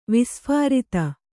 ♪ visphārita